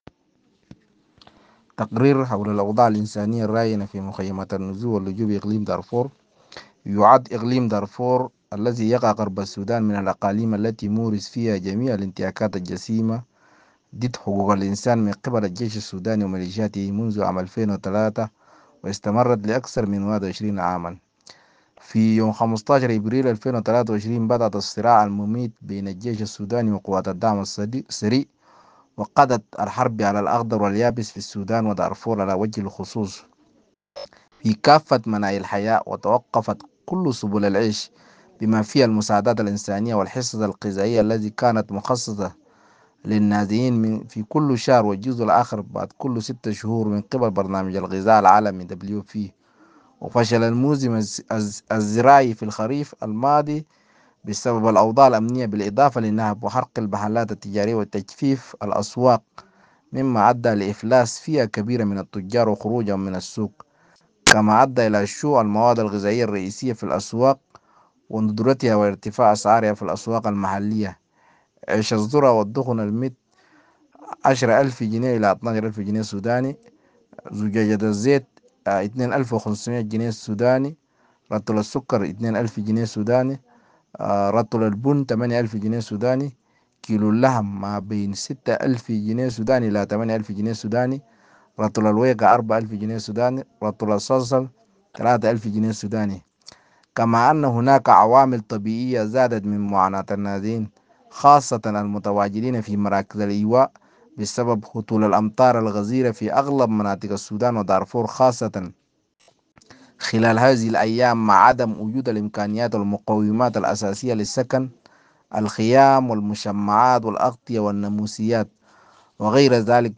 تقرير-حول-الأوضاع-الإنسانية-الراهنة-في-مخيمات-النزوح-واللجوء-بإقليم-دارفور-.ogg